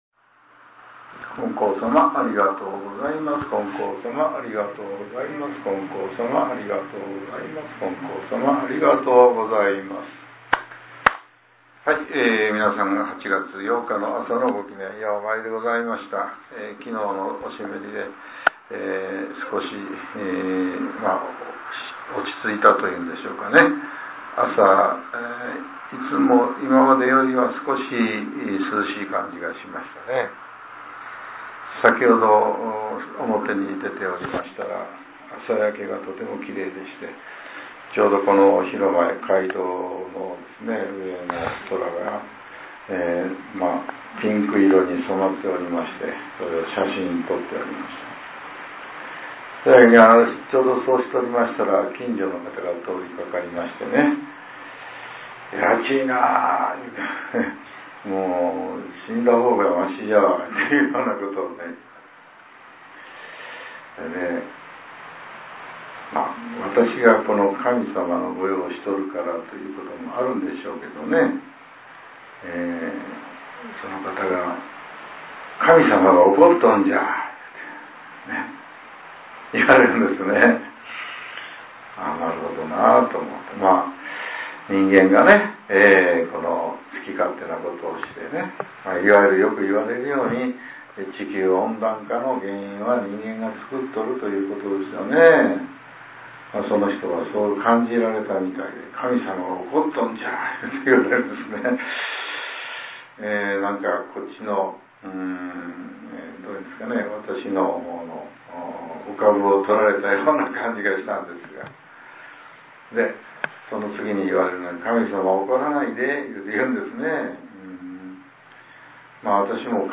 令和７年８月８日（朝）のお話が、音声ブログとして更新させれています。